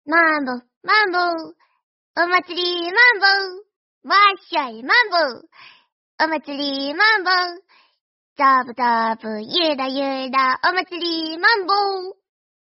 mambo matikanetannhauser Meme Sound Effect